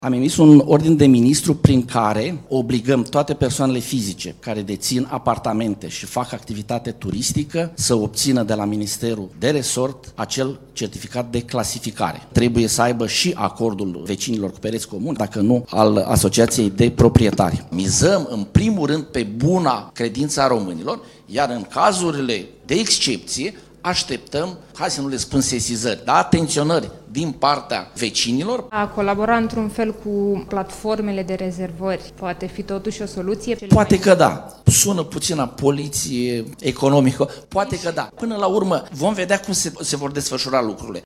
Toate persoanele fizice care închiriază apartamente pentru turiști vor fi obligate să obțină un certificat de clasificare. Documentul va fi acordat doar dacă există și acordul vecinilor, a spus ministrul Turismului, Constantin-Daniel Cadariu, la o conferință a Alianței pentru Turism.